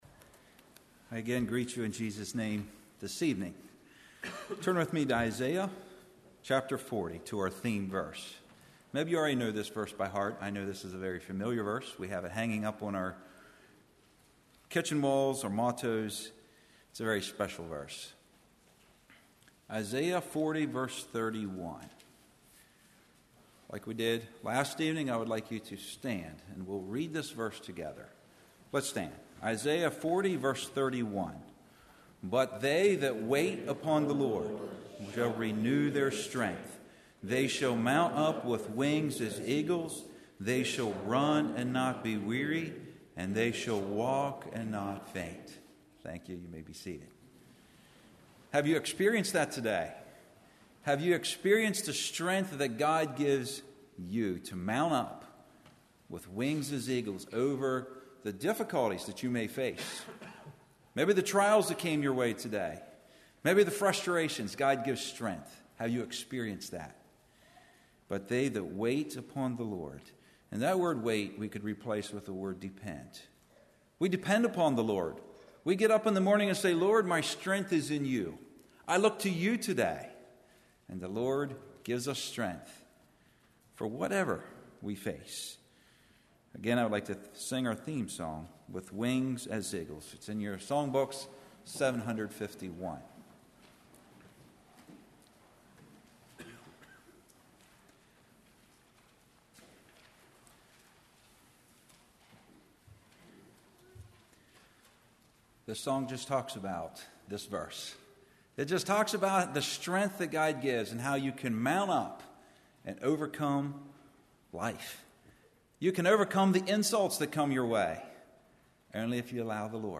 Congregation: Swatara Speaker